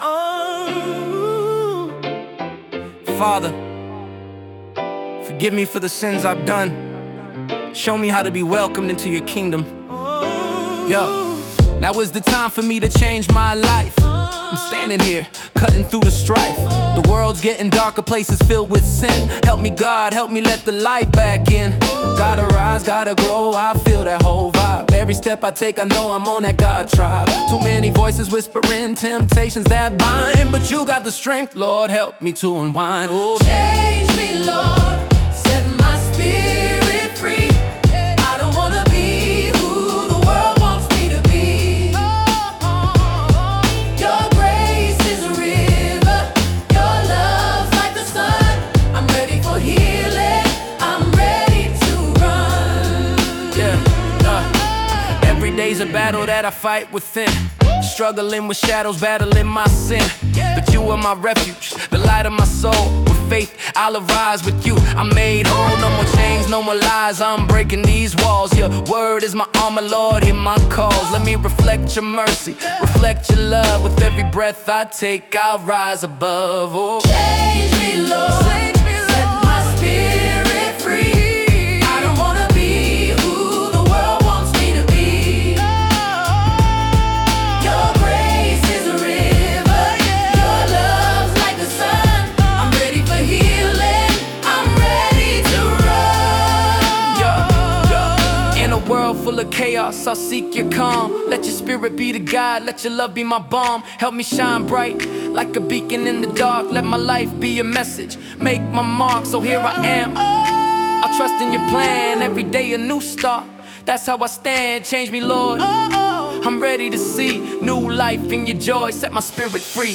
Genre: Gospel / Spiritual / Chant